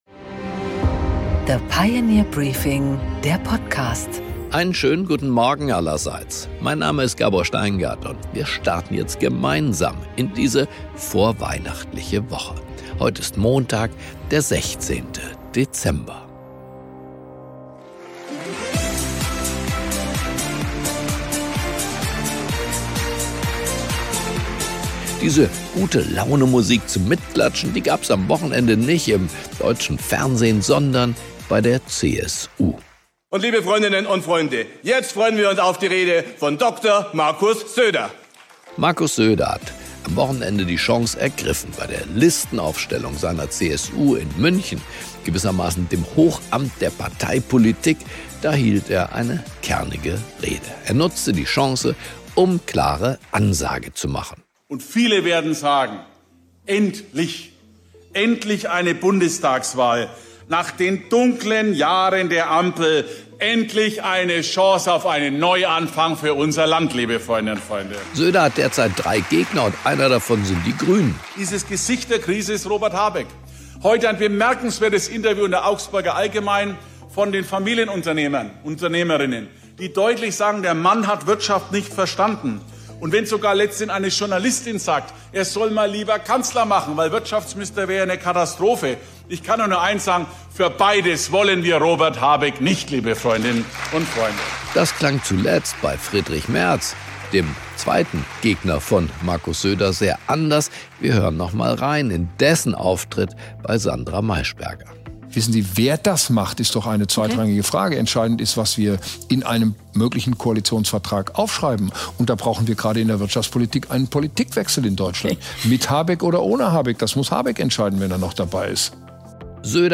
Gabor Steingart präsentiert das Pioneer Briefing
Im Interview: Prof. Manfred Güllner, Forsa-Chef, spricht mit Gabor Steingart über seine demoskopischen Erkenntnisse im Wahlkampf, die Strategien der Parteien und welche Koalition sich die Deutschen wünschen.